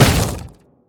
biter-roar-behemoth-7.ogg